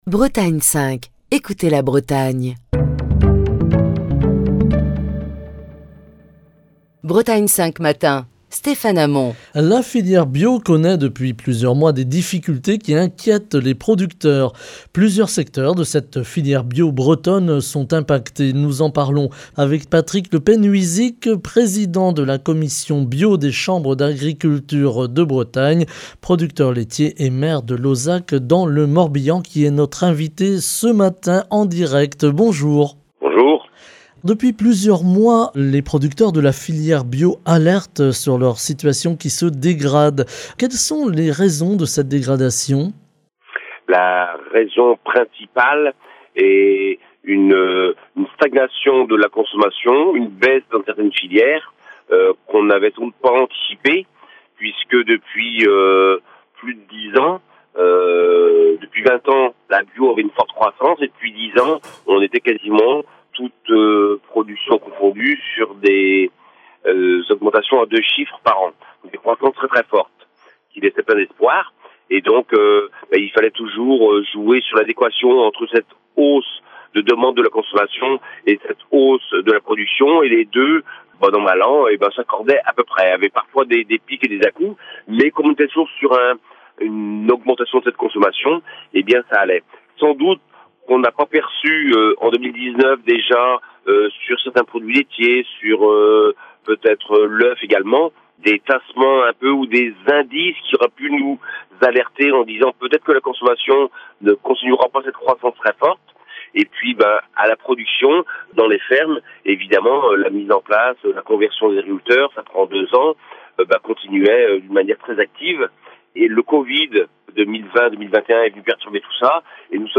La filière bio connaît depuis plusieurs mois des difficultés qui inquiètent les producteurs. Plusieurs secteurs de cette filière bio bretonne sont impactés. Nous en parlons avec Patrice Le Penhuizic, président de la commission bio des Chambres d'agriculture de Bretagne, producteur laitier et maire de Lauzach dans le Morbihan, qui est notre invité ce matin.